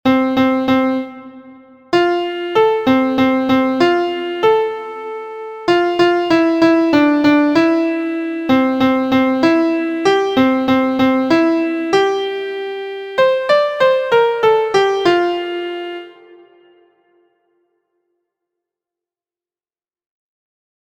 • Origin: Mexican Folk Song
• Key: F Major
• Time: 3/4
• Form: ABAC
• Musical Elements: notes: half, dotted quarter, quarter, eighth; pickup beat, Latin rhythm